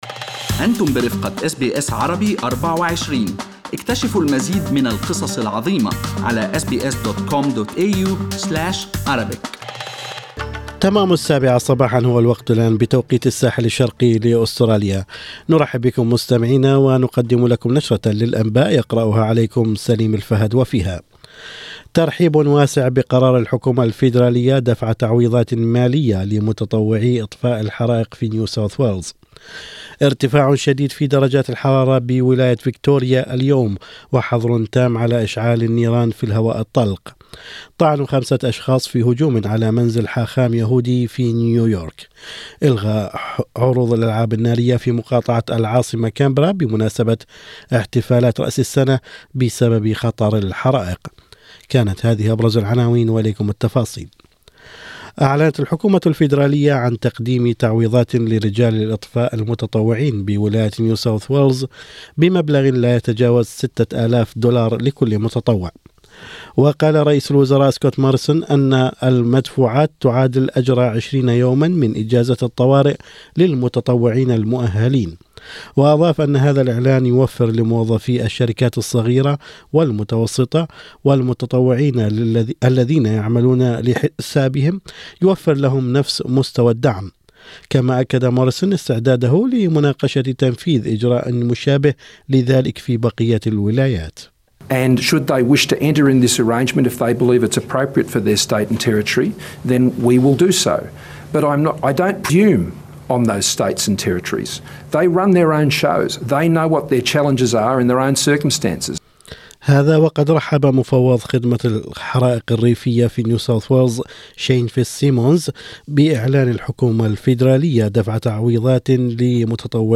أخبار الصباح: اجواء حارة جداً في فيكتوريا وخطر الحرائق "شديد"